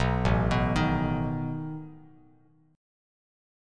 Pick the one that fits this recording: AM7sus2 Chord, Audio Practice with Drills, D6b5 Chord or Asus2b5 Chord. Asus2b5 Chord